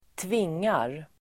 Uttal: [²tv'ing:ar]